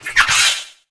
Grito de Impidimp.ogg
) Categoría:Gritos de Pokémon de la octava generación Categoría:Impidimp No puedes sobrescribir este archivo.
Grito_de_Impidimp.ogg.mp3